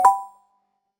notification-1.mp3